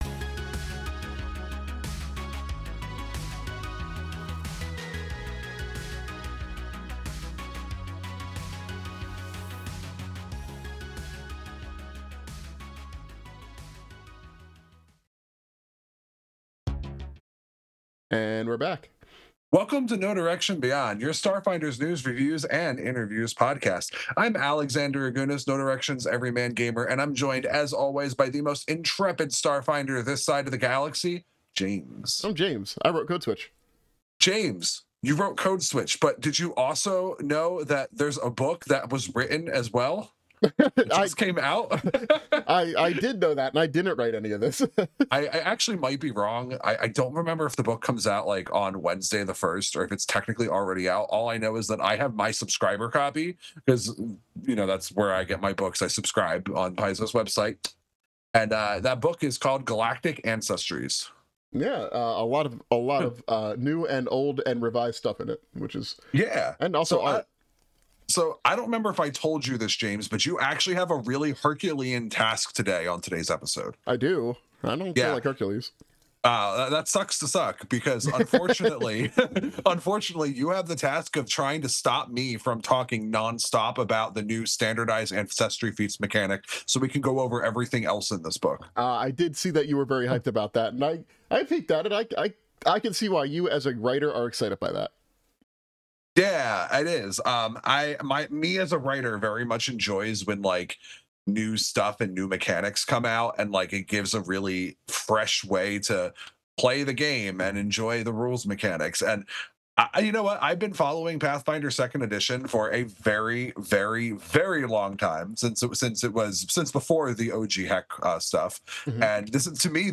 Recorded live on Twitch.